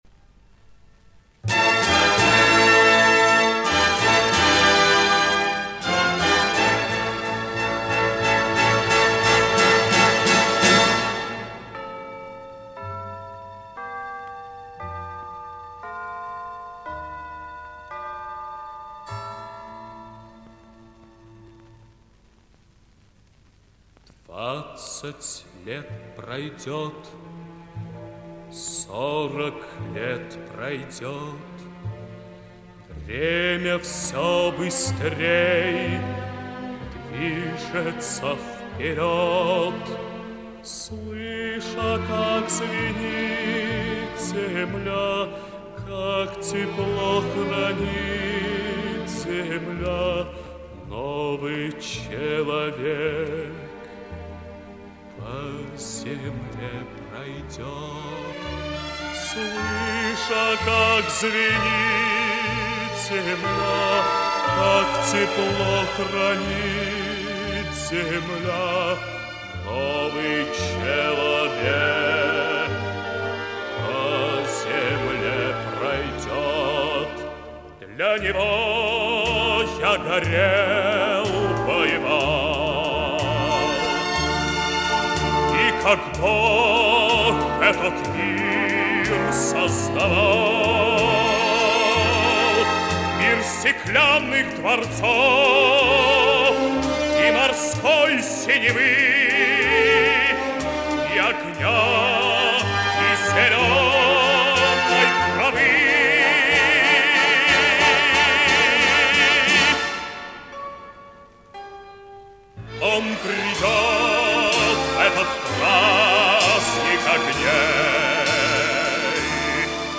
Оцифровал сам, качество выше, чем у "Народа".